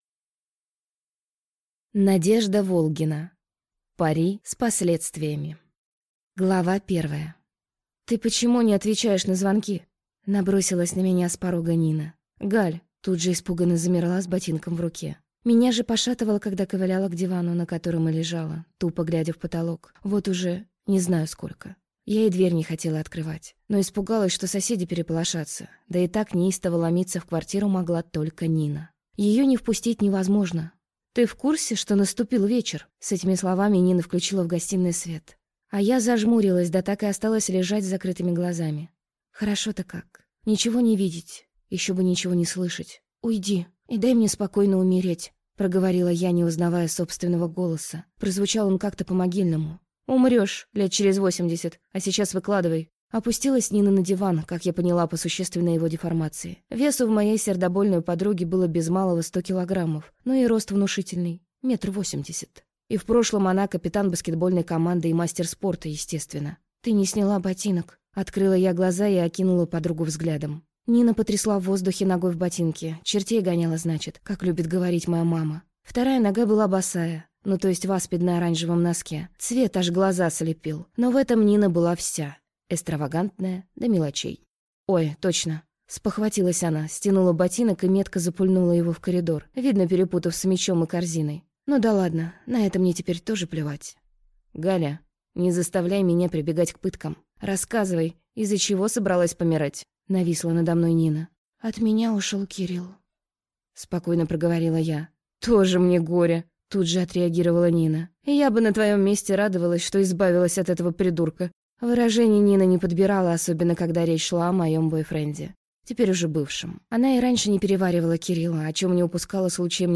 Аудиокнига Пари с последствиями | Библиотека аудиокниг
Прослушать и бесплатно скачать фрагмент аудиокниги